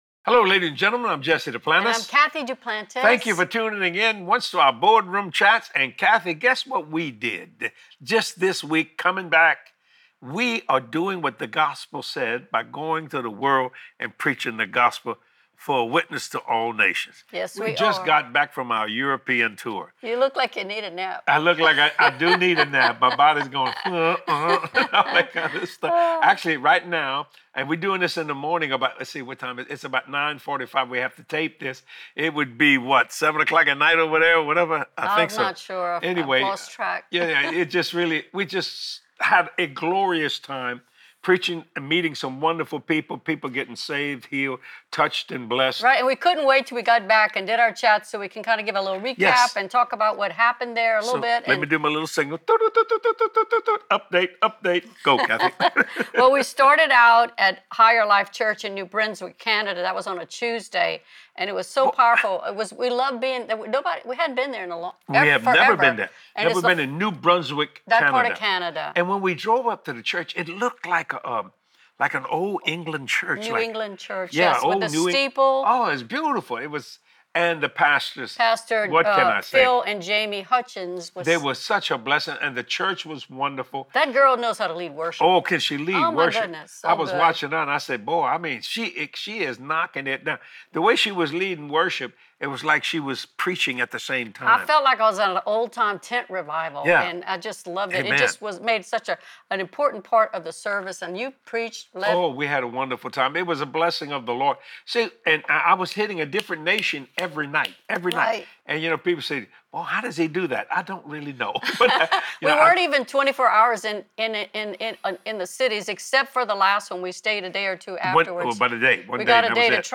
In this powerful chat